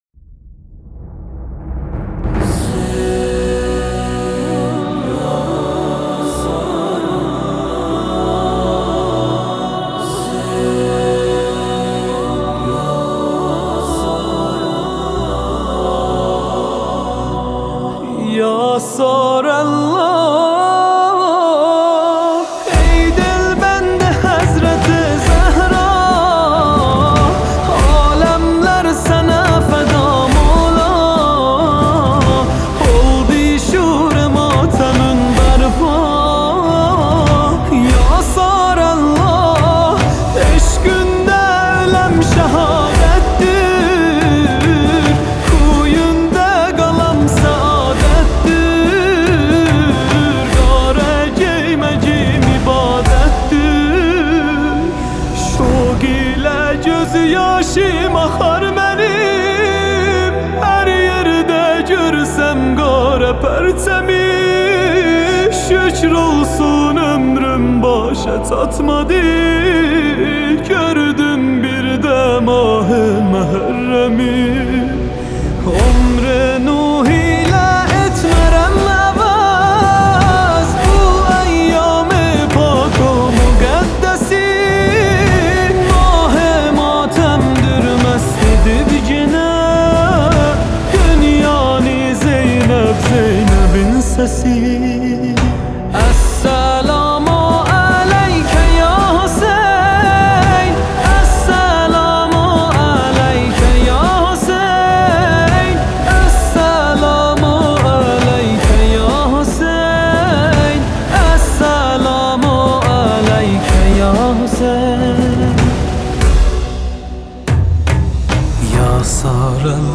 دانلود مداحی ترکی